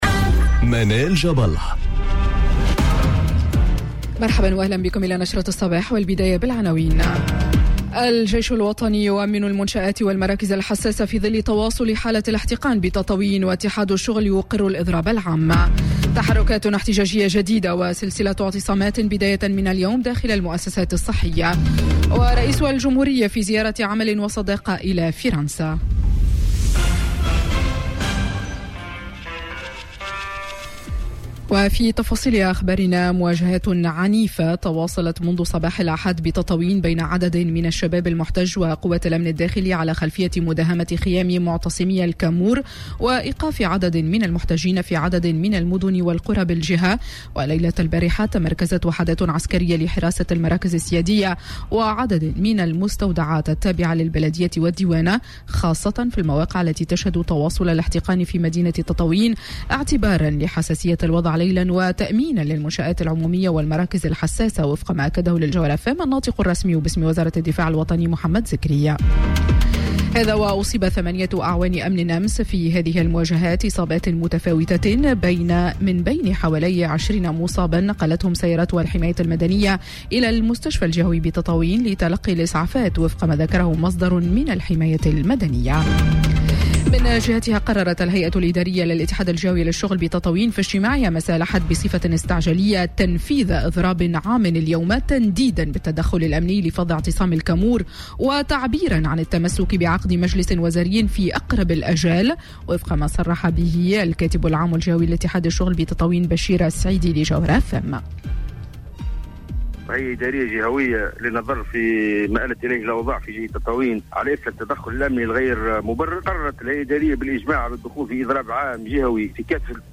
نشرة أخبار السابعة صباحا ليوم الإثنين 22 جوان 2020